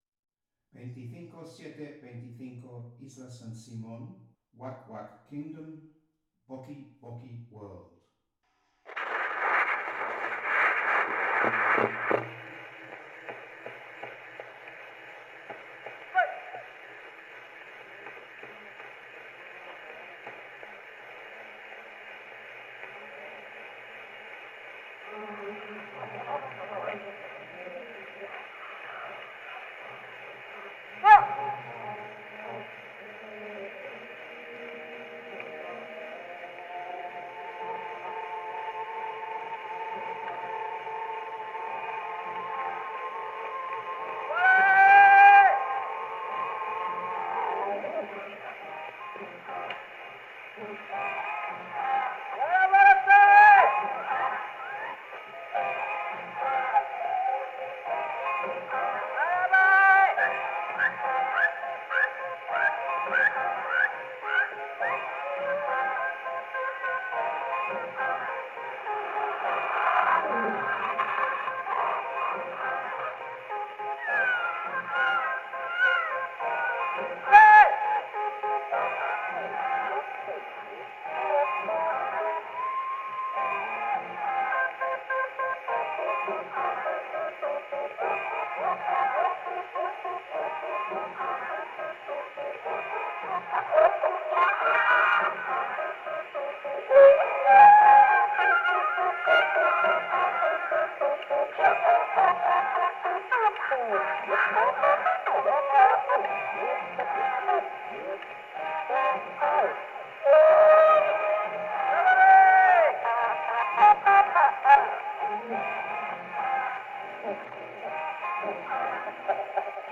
Original sound from the phonographic cylinder.
Recording place: Espazo Cafetería